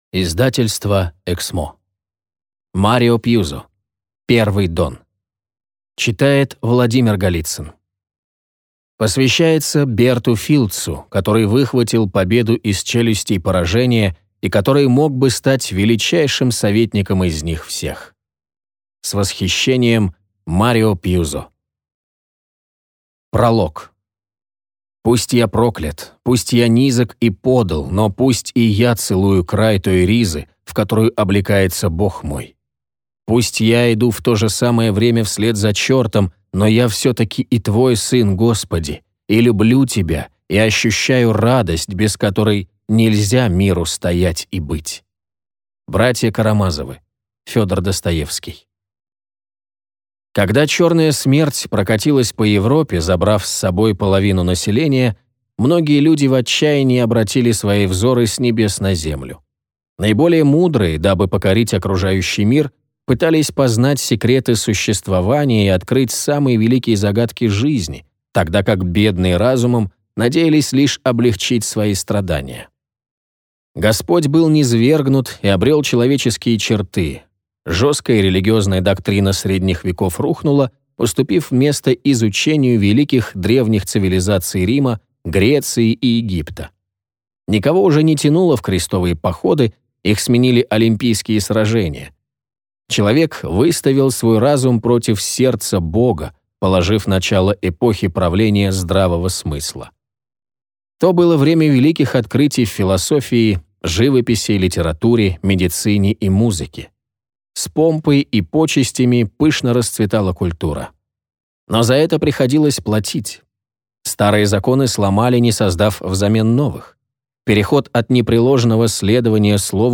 Аудиокнига Первый дон | Библиотека аудиокниг